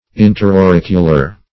Search Result for " interauricular" : The Collaborative International Dictionary of English v.0.48: Interauricular \In`ter*au*ric"u*lar\, a. (Anat.)